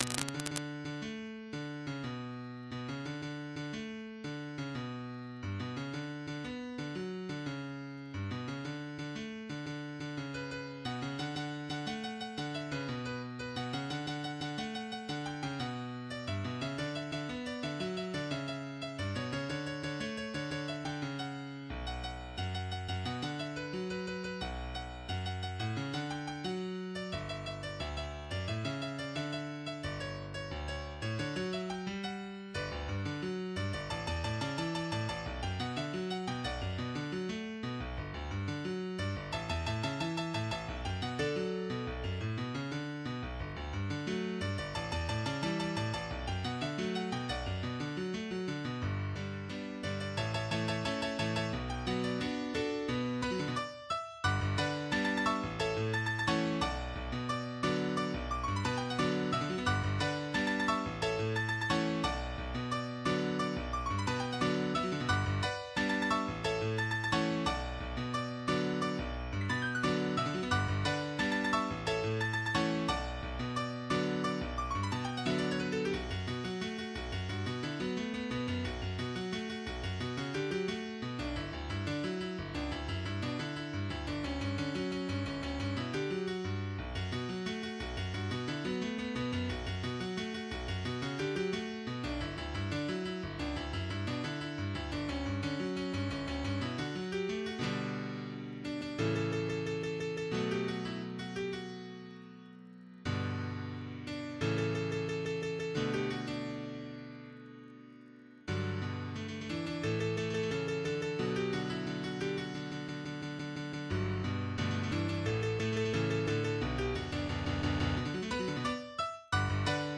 MIDI 18.09 KB MP3 (Converted) 3.48 MB MIDI-XML Sheet Music